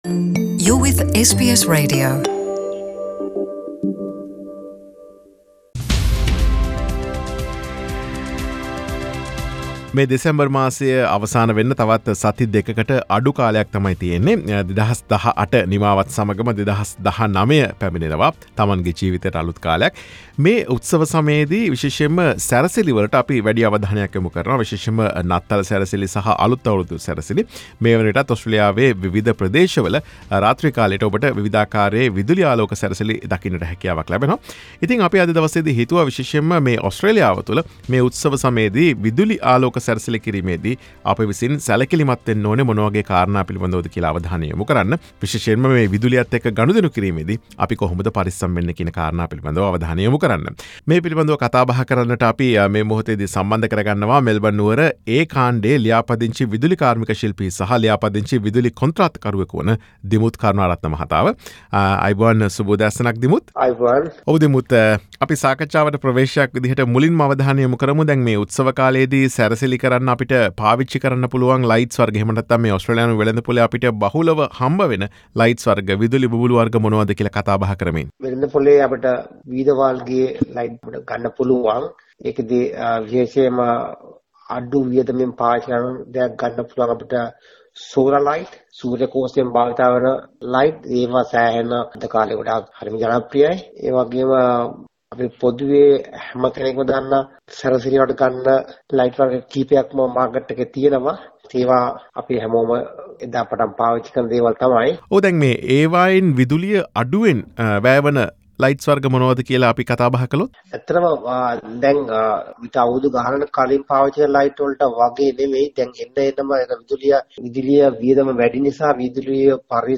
සාකච්ඡාව